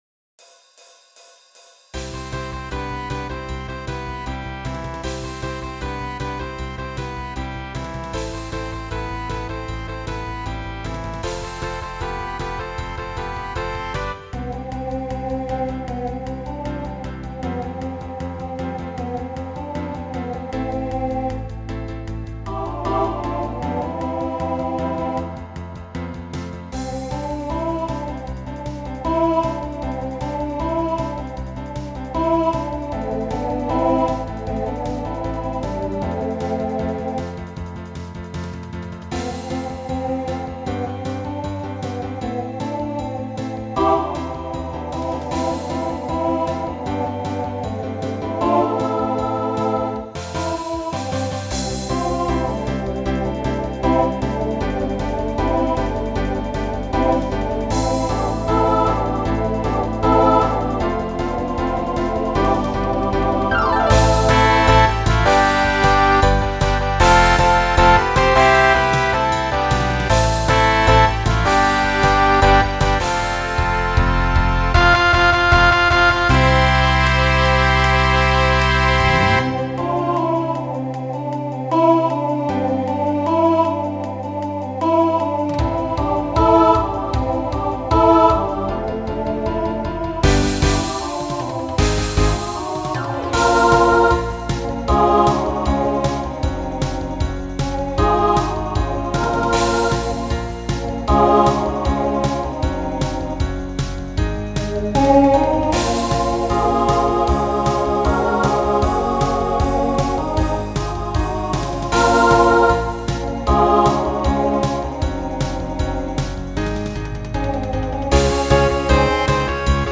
Voicing SATB Instrumental combo Genre Pop/Dance